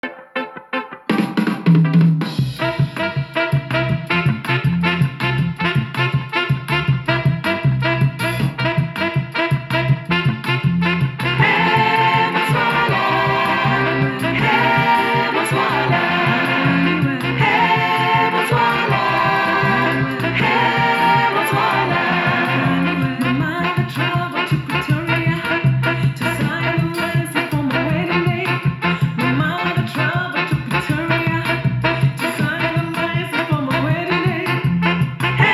Music sample: